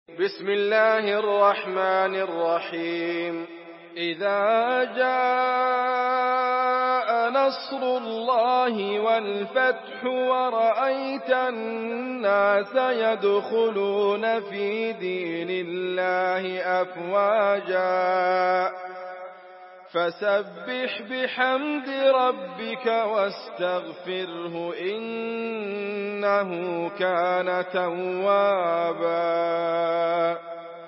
Surah Nasr MP3 in the Voice of Idriss Abkar in Hafs Narration
Murattal Hafs An Asim